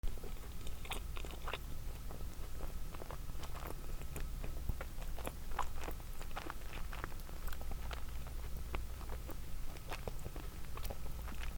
食べる そしゃくする
『グチュグチュ』